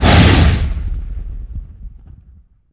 punch1.ogg